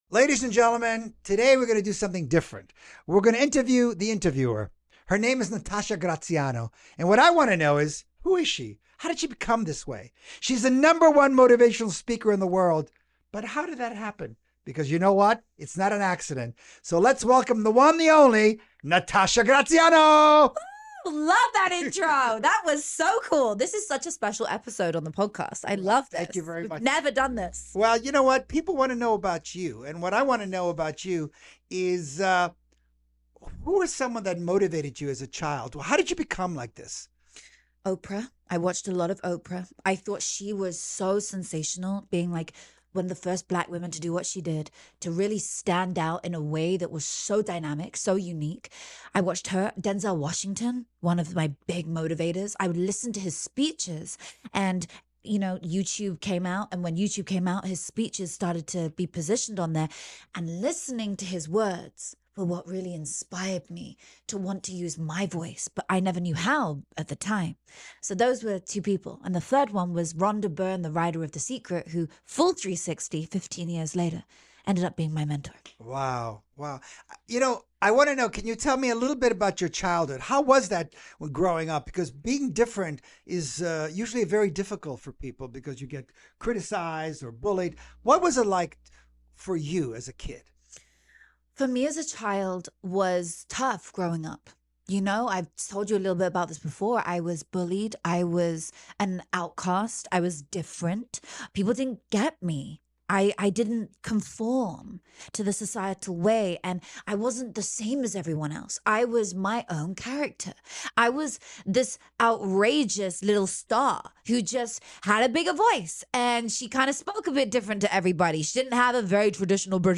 is the one being interviewed